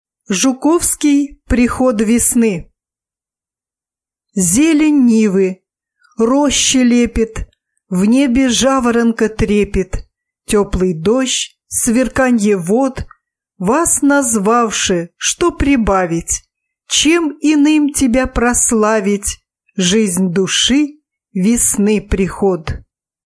На данной странице вы можете слушать онлайн бесплатно и скачать аудиокнигу "Приход весны" писателя Василий Жуковский.